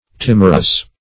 Timorous \Tim"or*ous\, a. [LL. timorosus, from L. timor fear;